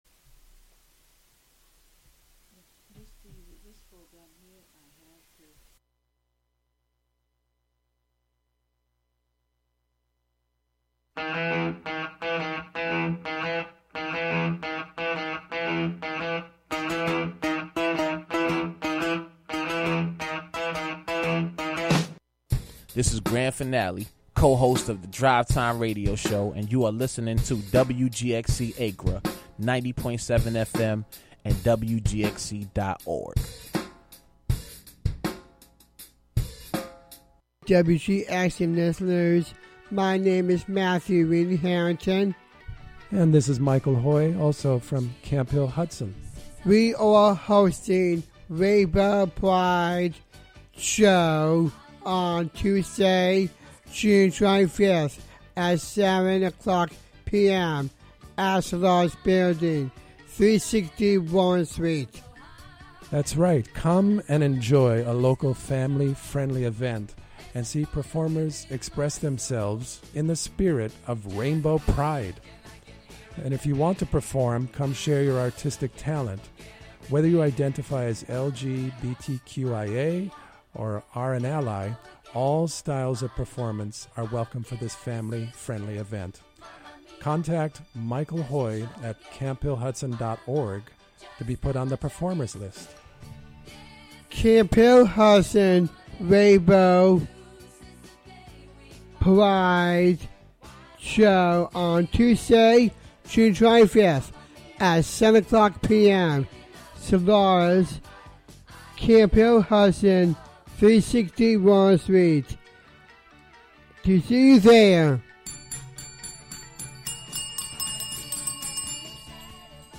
July 4th we celebrate the Declaraction of Independence, and today we are celebrating July Independence month in Latin America, presenting Independence day from Argentina, Colombia, Peru, and Venezuela. Also to celebrate July 4 we will be broadcasting a rarely played Star Spangled Banner in Spanish that was commissioned by President Franklin Roosevelt in 1945, as part of his Good Neighbor Policy with Latin America, sung by the Nurse Hero Hispanic Star Choir.
“Nuestra Música,” “Our Music,” presents the rhythms and folklore of Latin America, Spain, Portugal and Cape Verde.
Among many styles we present: Tango, Chacarera, Baguala, Zamba with a Z and Samba with and S, Bossa Nova, Cumbia, Llanero, Vallenato, Merengue, Parrandera, Son, Bolero, Ranchera, Corrido, Marimba, Fado, Flamenco, Sevillanas, Jota, Blues, Jazz, Danzon, Mambo and many more.